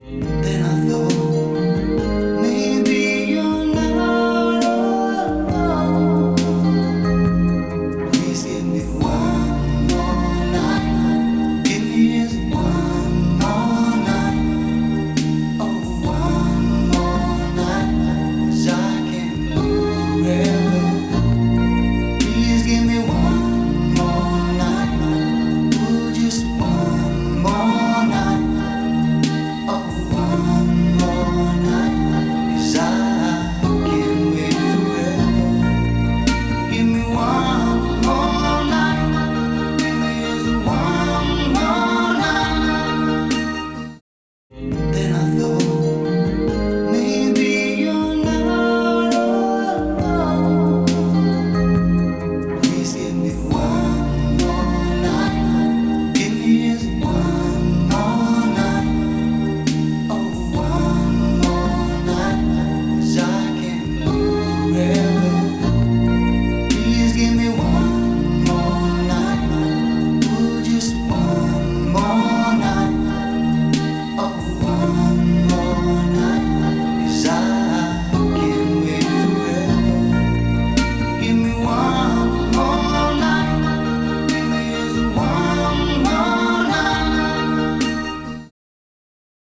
Записал на Behringer ECM8000. Громкость и всё остальное не менял.
Первым звучит от Warm audio потом Quik Lok Теперь думаю что брать?